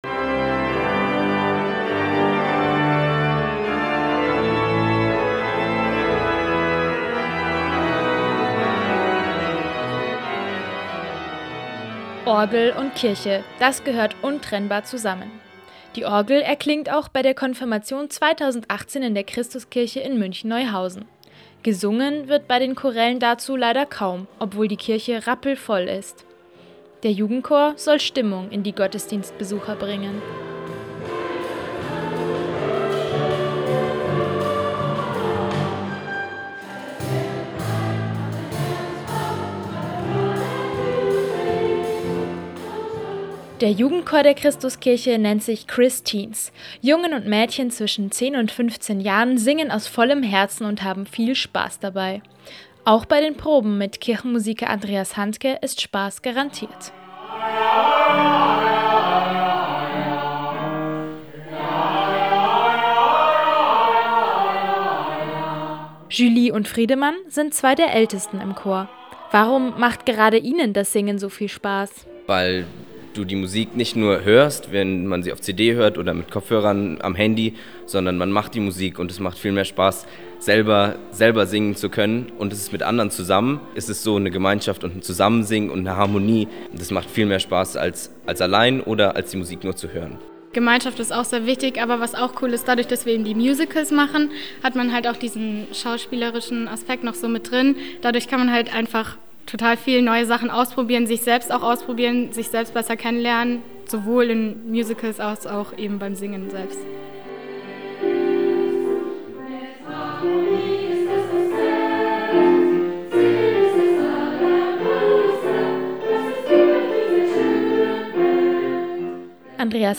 Probe vor den Pfingstferien: Das heißt heute Wunschkonzert, Singen just for fun.
Es geht mit Spaß zur Sache, es werden Witze gemacht, um dann wieder konzentriert weitersingen zu können.
Radiobeitrag_ChrisTeens.mp3